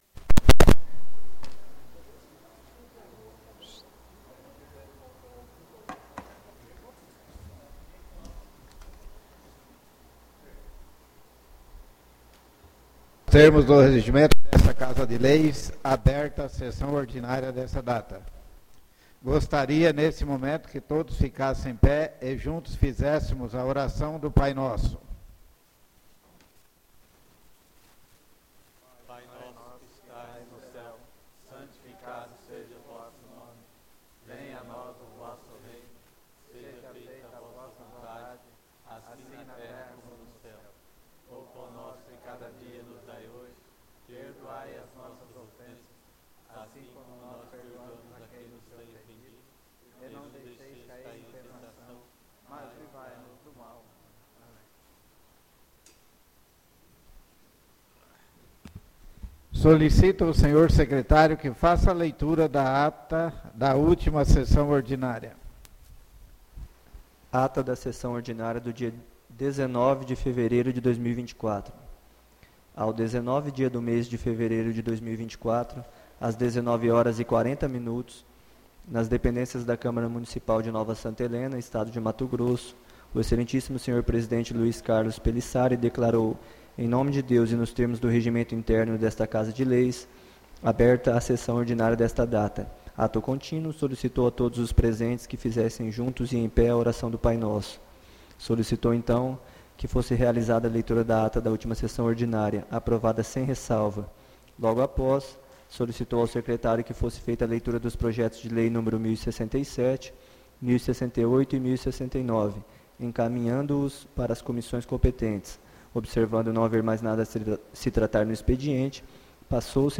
ÁUDIO SESSÃO 26-02-24